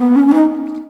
Flute 51-05.wav